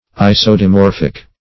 Isodimorphic \I`so*di*mor"phic\, a.
isodimorphic.mp3